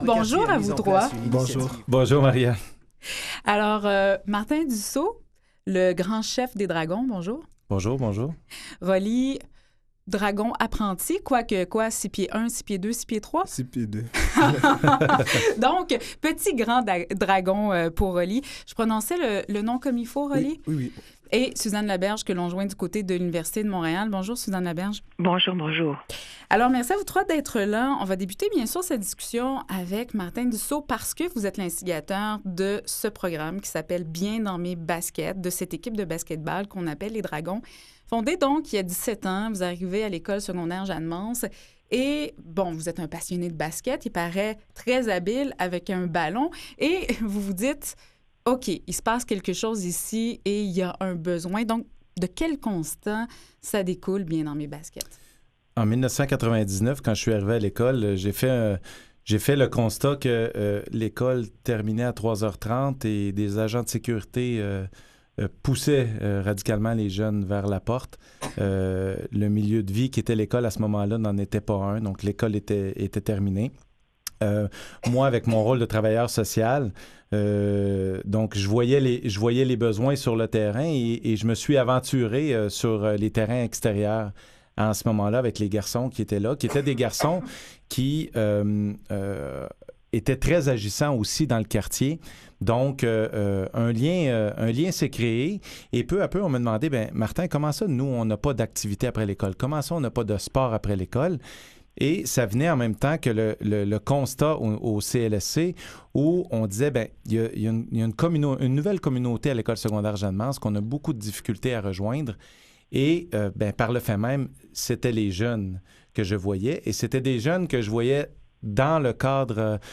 Aujourd’hui, une table ronde ayant comme angle principal le rôle du sport dans la persévérance scolaire.